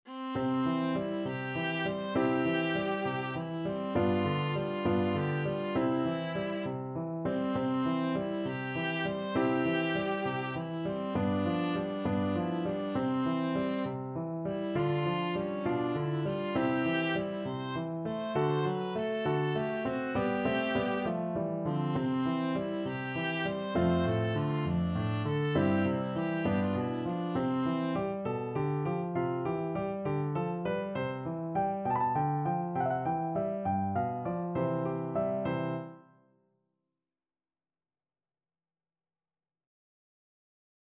Viola
C major (Sounding Pitch) (View more C major Music for Viola )
6/8 (View more 6/8 Music)
~ = 100 Fršhlich
Easy Level: Recommended for Beginners with some playing experience
Classical (View more Classical Viola Music)